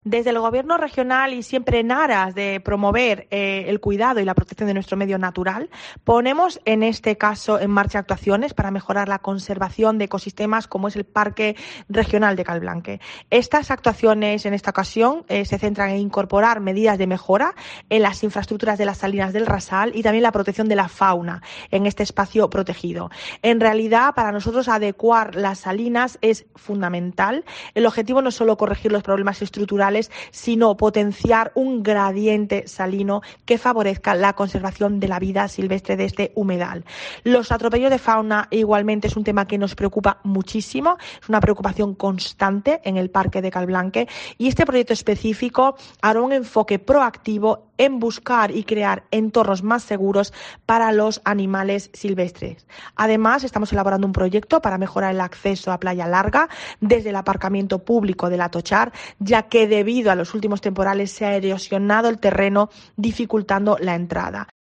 María Cruz Ferreira, secretaria autonómica de Energía, Sostenibilidad y Acción Climática